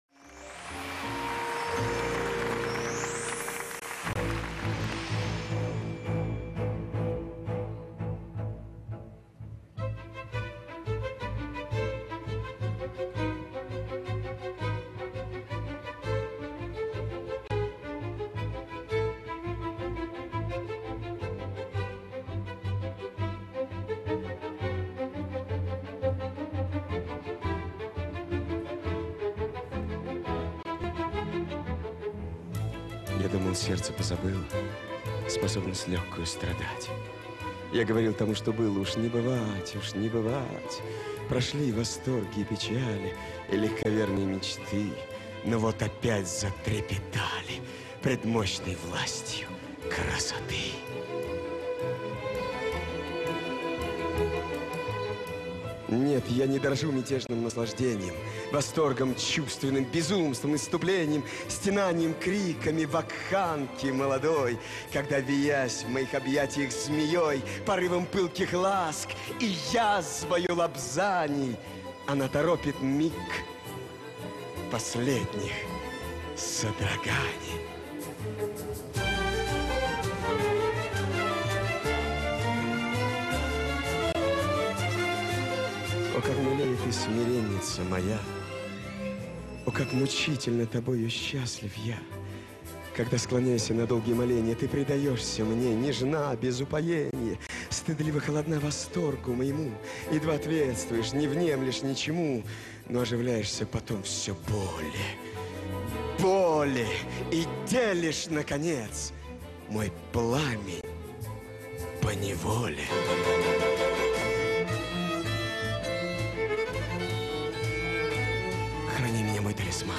Новогоднее шоу - 2012. Запись в Московском международоном Доме музыки.